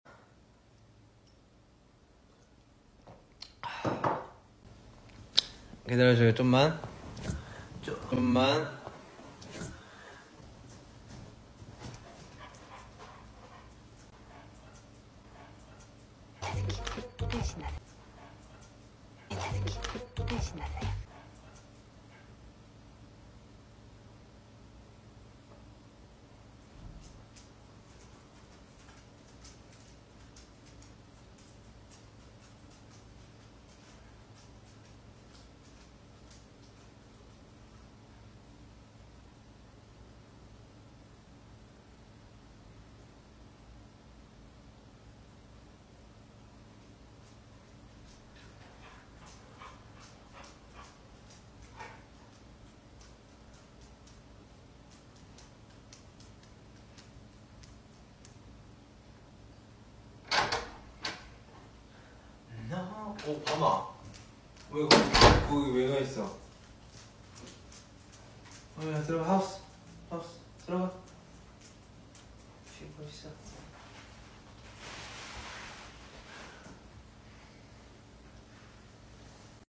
AI ESCULLEN PONGAN AURICULARES 🎧 SE ESCULLA COMO SUSURRA A BAM